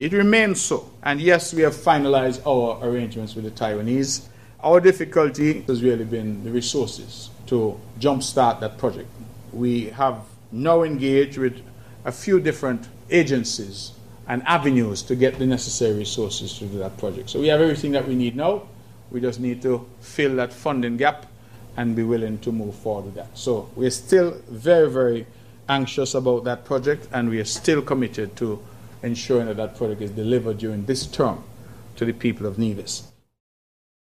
That was posed to Nevis’ Premier, the Hon. Mark Brantley by the VONNEWSLINE during his monthly press conference on Sep. 30th.